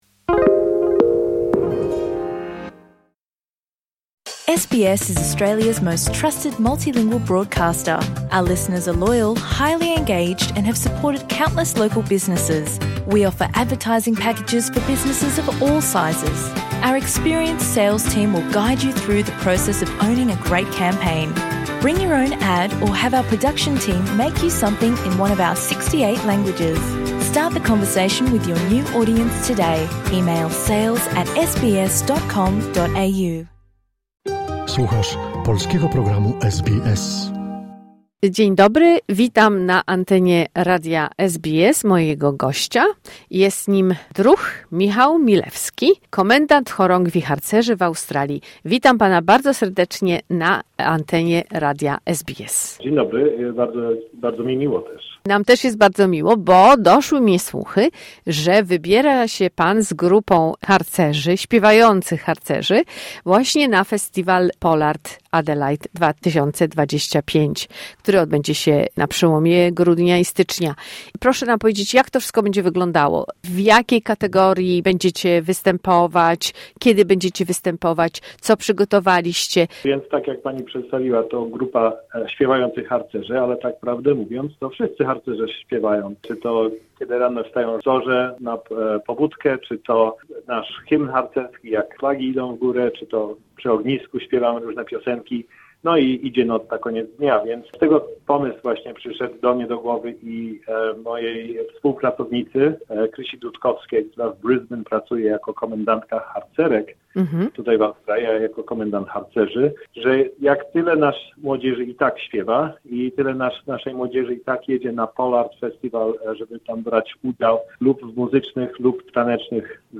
Posłuchaj całej godzinnej audycji radiowej tutaj PolArt to tętniący życiem, festiwal dla każdego, który poprzez sztukę wizualną, taniec ludowy, teatr, muzykę, literaturę z dumą pielęgnuje piękno i bogactwo polskiej kultury.